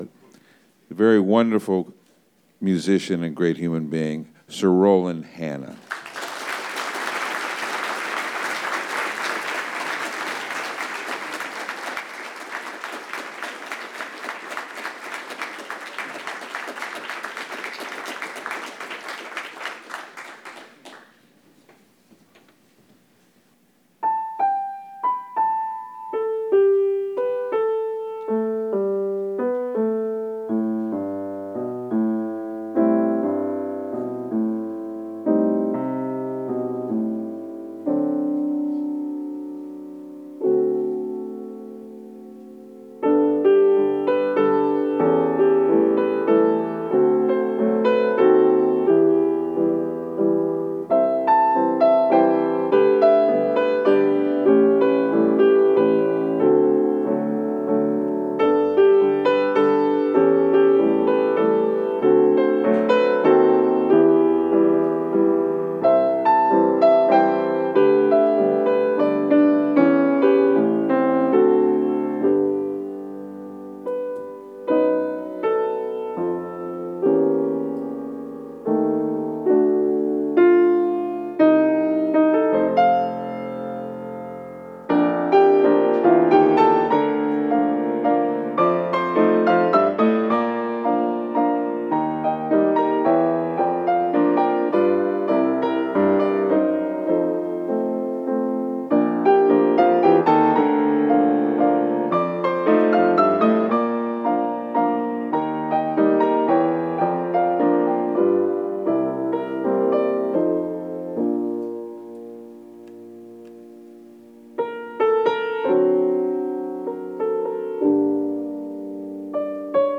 recorded at The Stanley Kaplan Penthouse, Lincoln Center -